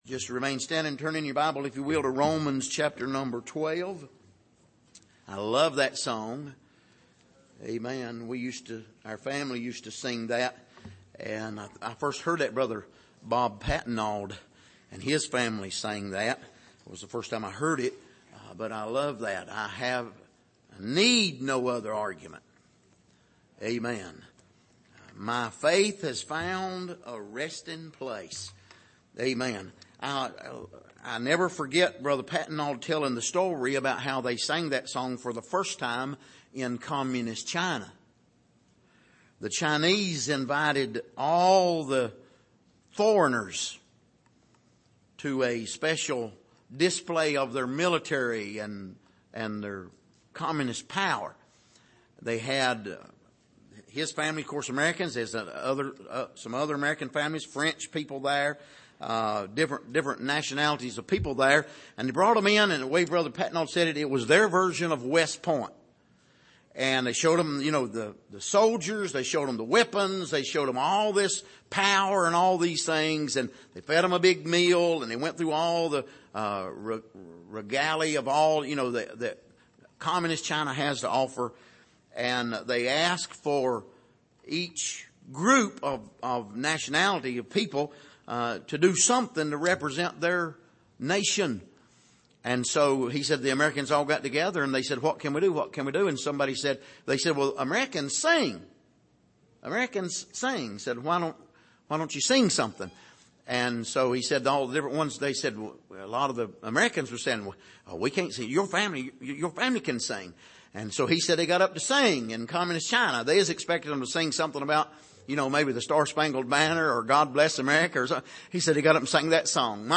Passage: Romans 12:15-21 Service: Sunday Morning